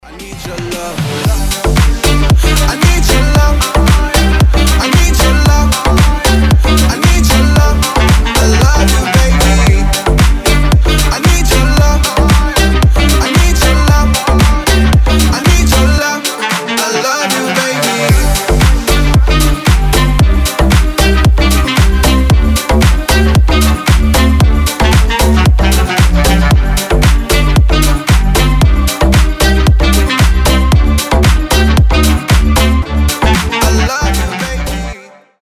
• Качество: 320, Stereo
мужской голос
deep house
Club House